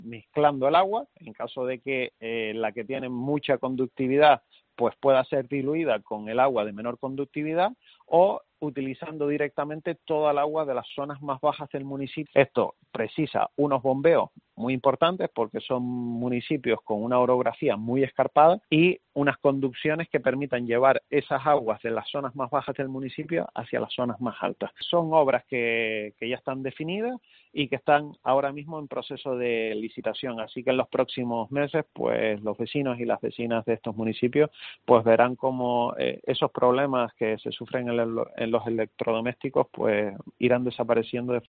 Javier Rodríguez, consejero de Desarrollo Sostenible del Cabildo de Tenerife